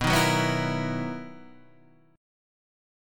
BM11 chord {7 6 4 4 5 6} chord